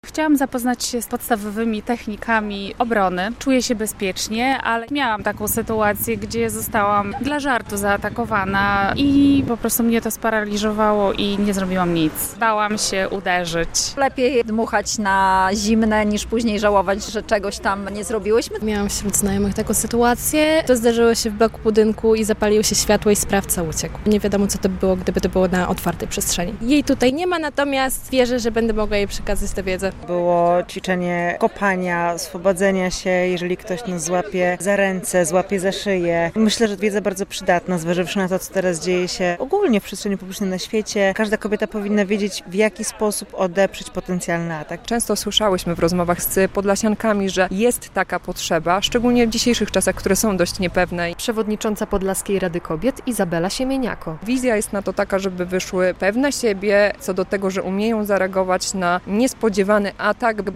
Warsztaty samoobrony dla kobiet - relacja